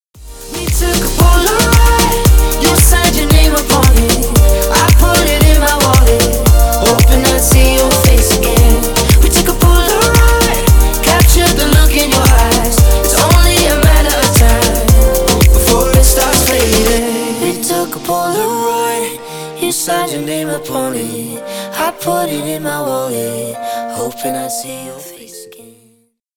Поп Музыка
зарубежные # весёлые